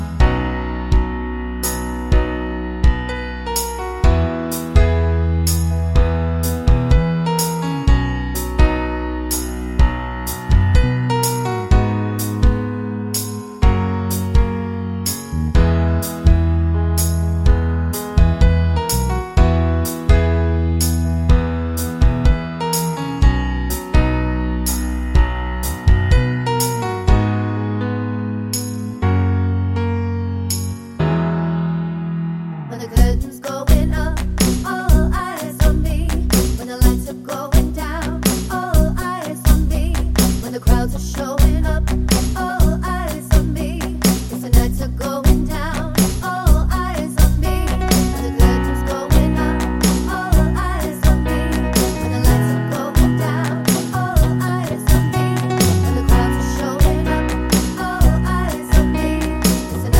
no Backing Vocals Musicals 2:59 Buy £1.50